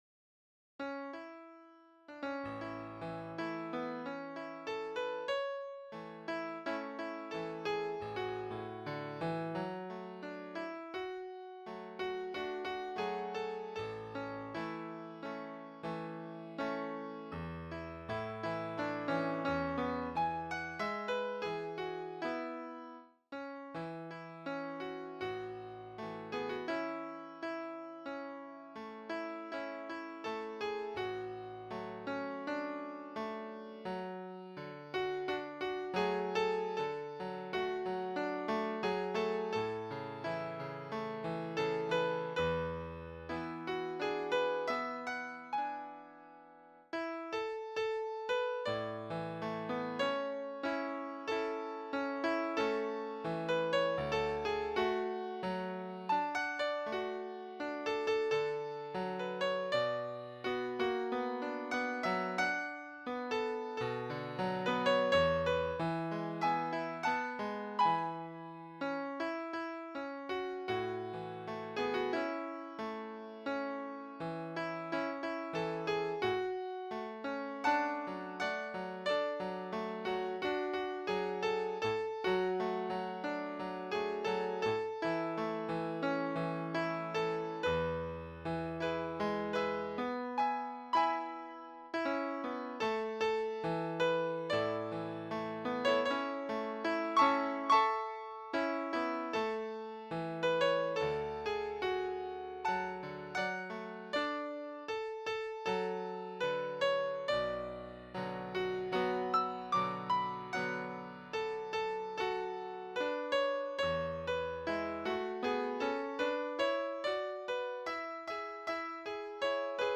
Partitura para piano / Piano score (pdf)
Escuchar partitura / Listen score (MP3)